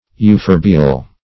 Search Result for " euphorbial" : The Collaborative International Dictionary of English v.0.48: Euphorbiaceous \Eu*phor`bi*a"ceous\, Euphorbial \Eu*phor"bi*al\, a. (Bot.)
euphorbial.mp3